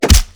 Impact_1.wav